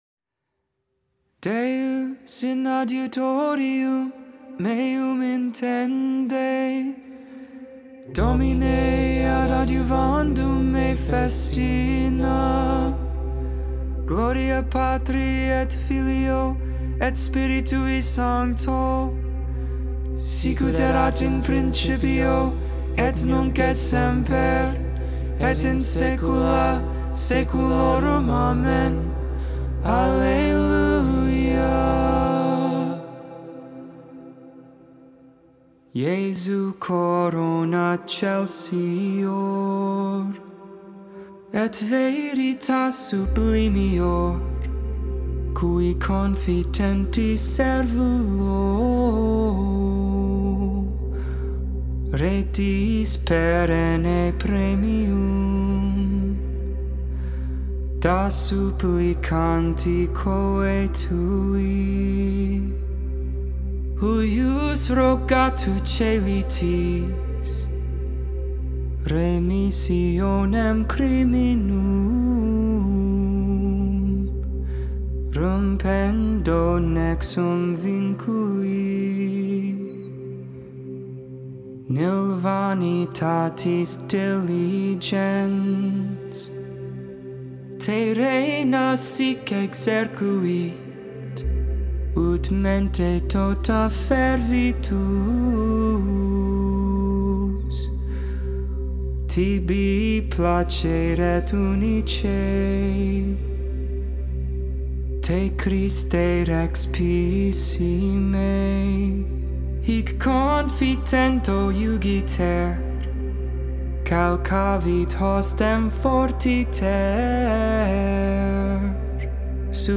6.21.22 Lauds, Tuesday Morning Prayer